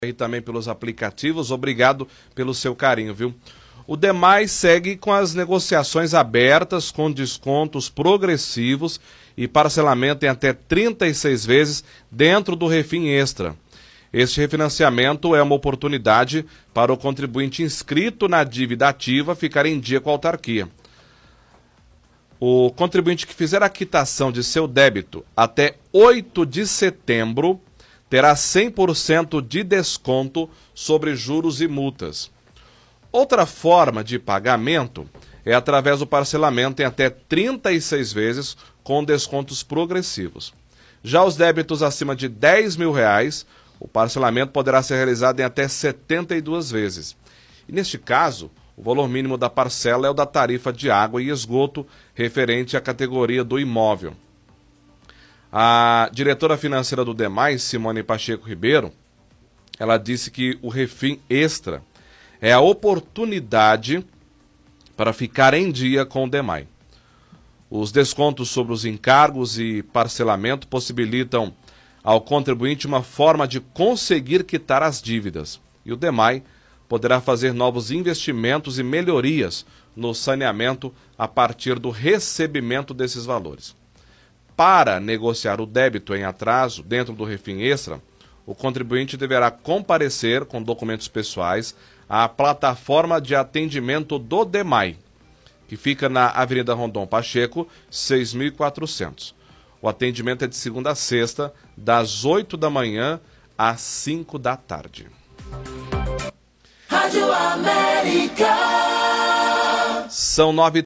Leitura de release